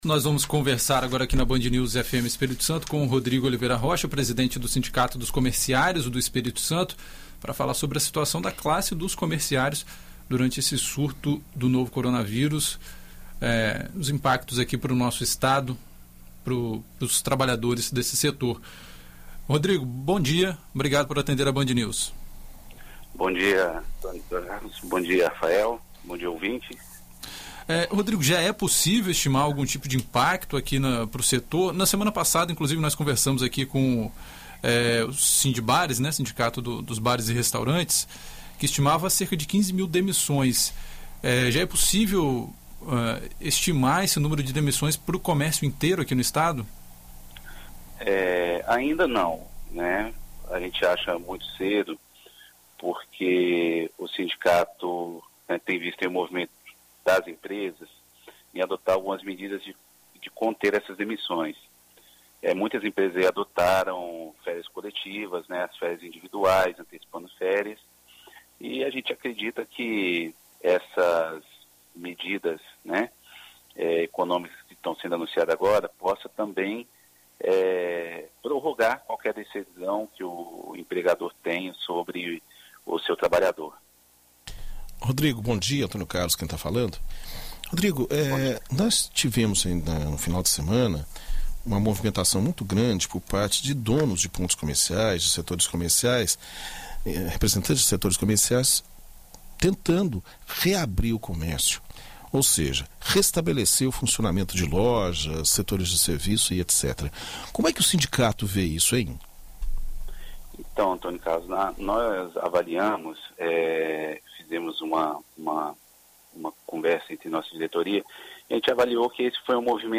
Em entrevista à BandNews FM